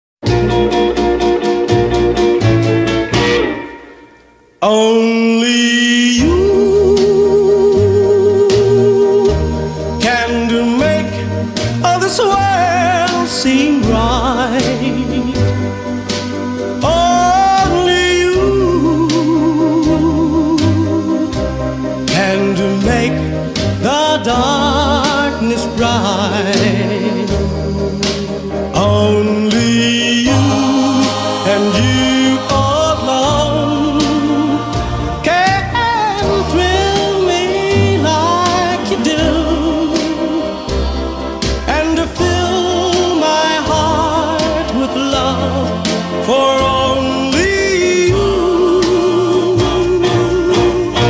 Nhạc Chuông Nhạc Phim